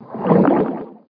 swimunder.mp3